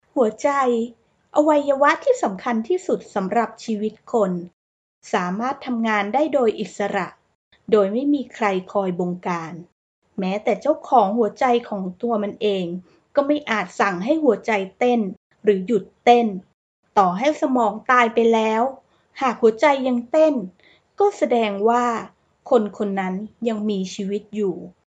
泰语样音试听下载
泰语配音员（女1） 泰语配音员（女2）